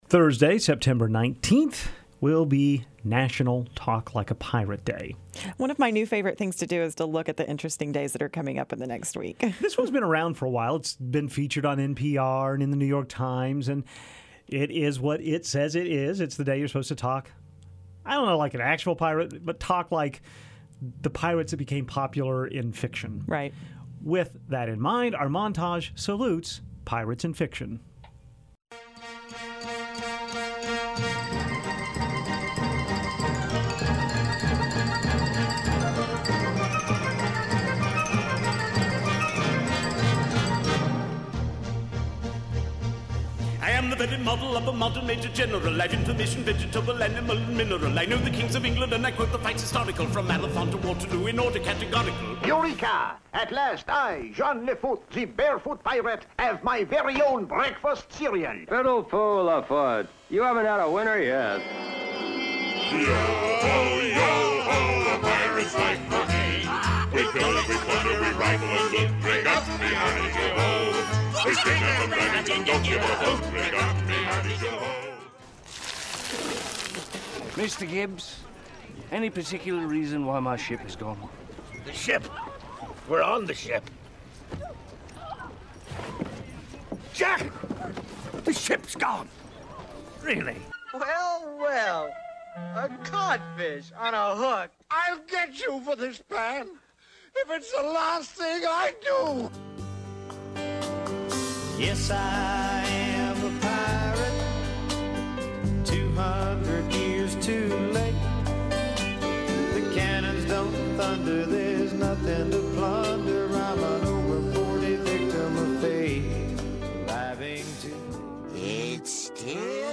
Here is a list of out clips celebrating swashbucklers, buccaneers and pirates: 1. A young Kevin Kline sings on the 1980 cast recording of Gilbert and Sullivan's The Pirates of Penzance. 2.
The song, Yo Ho Ho, A Pirate's Life for Me that was/is heard in the Pirates of the Caribbean ride. 4.Johnny Depp as Jack Sparrow as heard in the Pirates of the Caribbean movies. 5. An animated Captain Hook threatens Peter Pan in the Disney classic. 6.
Only one of two home runs to end a World Series. 10. Robert Newton as Long John Silver in the 1950 version of Treasure Island.